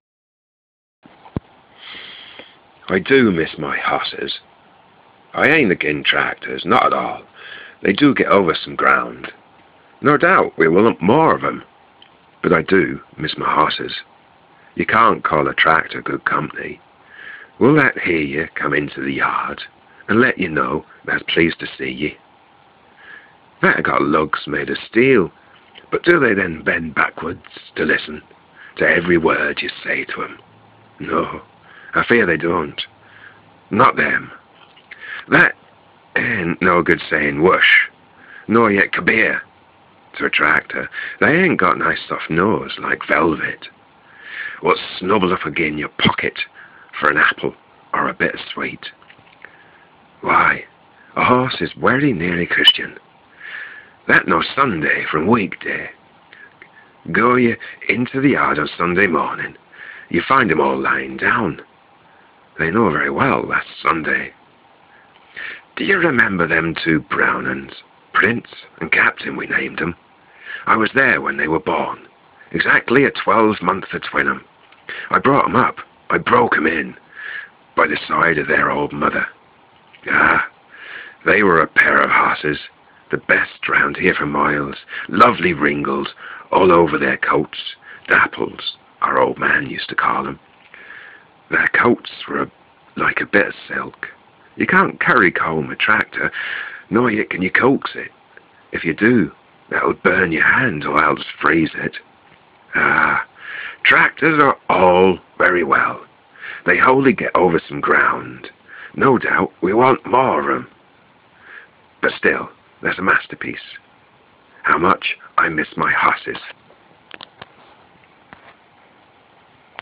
This poems a bit tricky to read because its written the way the farmer would have spoken it, he obviously came from the country and had what is called a local dialect or accent, if you read it just as it’s written down you can understand it much better.